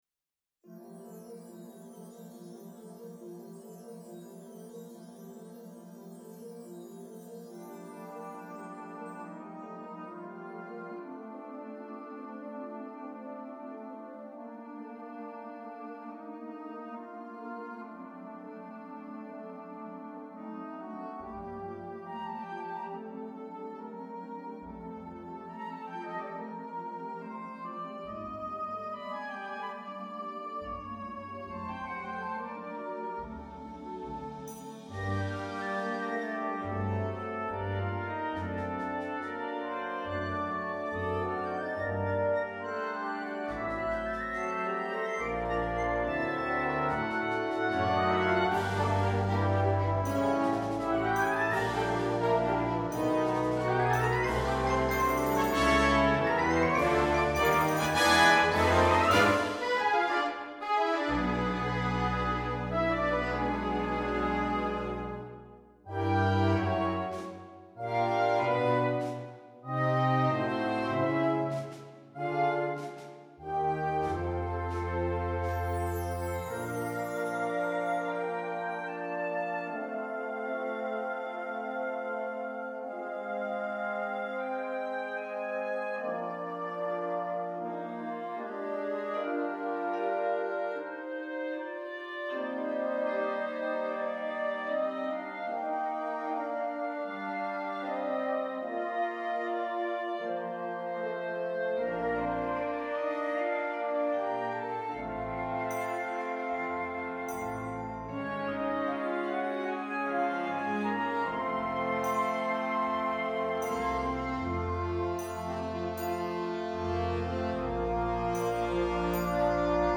fantāzija pūtēju orķestrim pēc pasakas motīviem